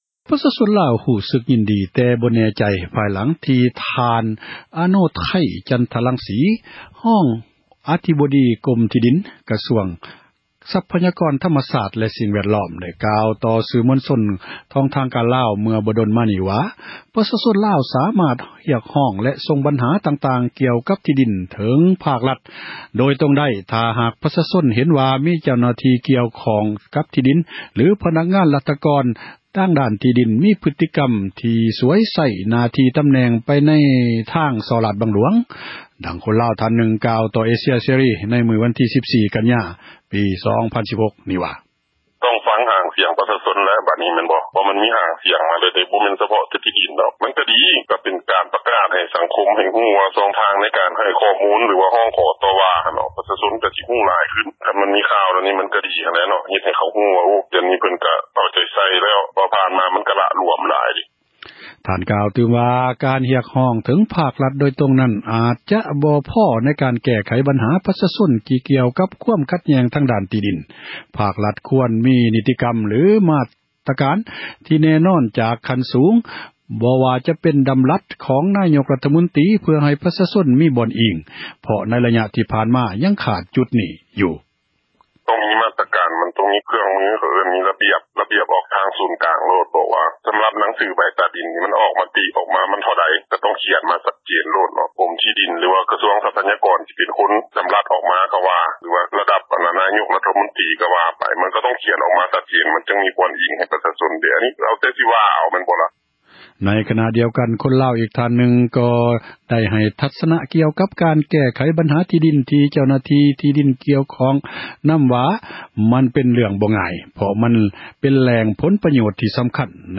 ດັ່ງ ຄົນລາວ ທ່ານນຶ່ງ ກ່າວຕໍ່ ຜູ້ສື່ຂ່າວ ເອເຊັຽ ເສຣີ ເມື່ອວັນທີ 14 ກັນຍາ 2016 ນີ້ ວ່າ: